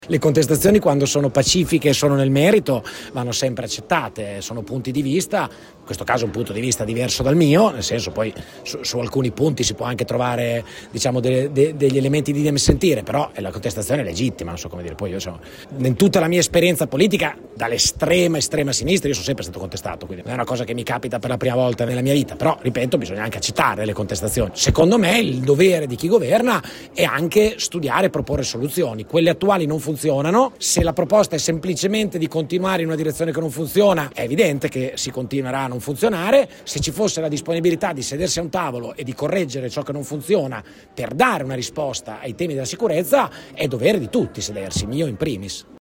“Secondo me il dovere di chi governa è proporre soluzioni. Quelle attuali non funzionano” ha detto il presidente de Pascale: